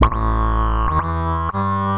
meow.mp3